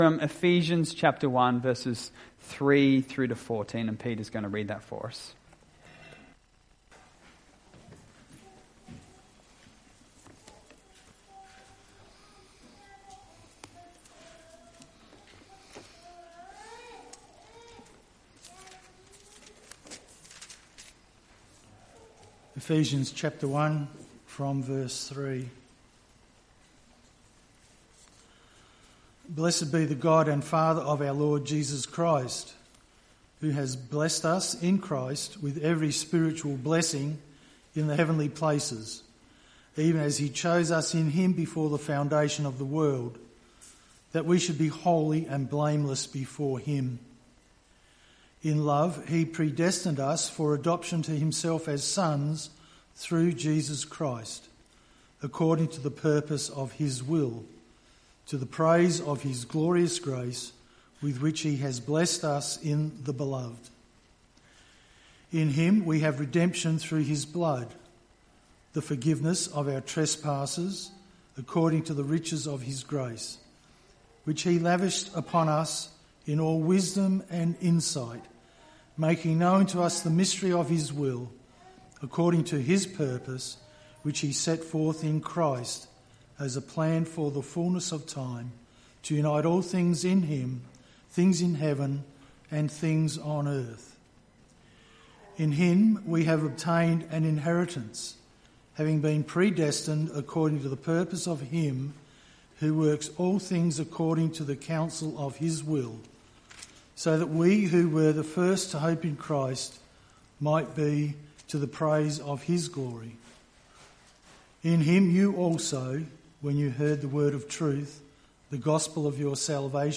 Jul 06, 2025 Limited Atonement: Definite and Personal MP3 SUBSCRIBE on iTunes(Podcast) Notes Sermons in this Series Limited Atonement: Definite and Personal; Readings: Ephesians 1:3-14 John 10:11-18 Limited Atonement: Definite and Personal